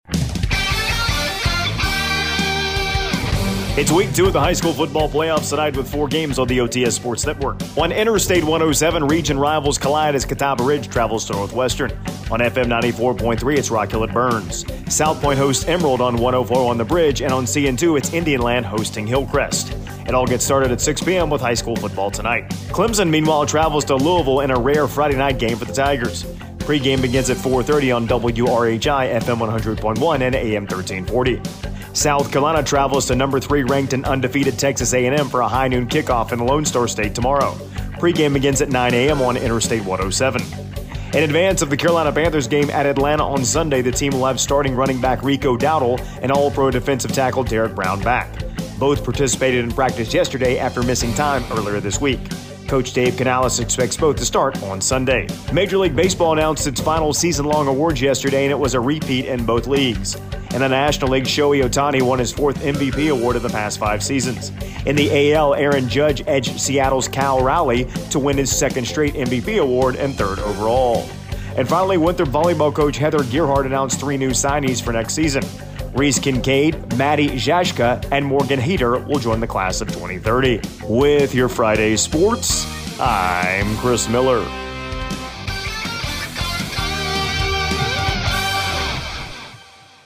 AUDIO: Tuesday Morning Sports Report